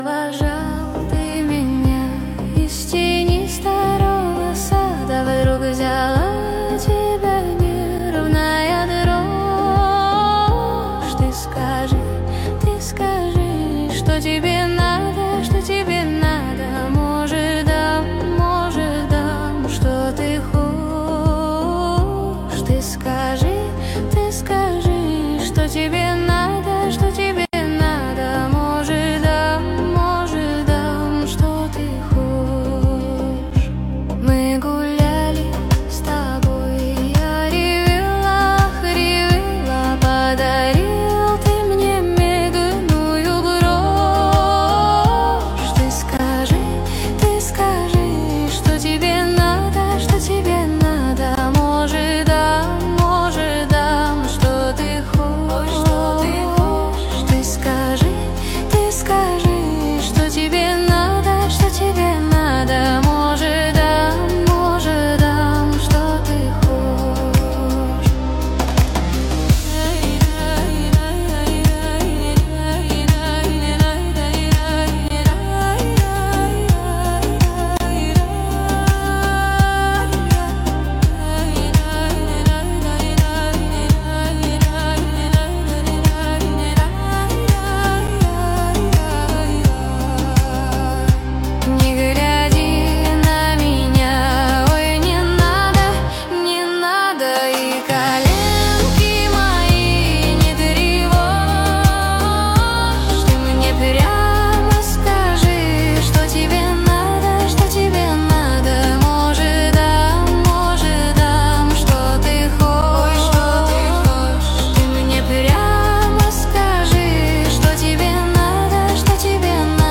Качество: 320 kbps, stereo
кавер нейросеть ИИ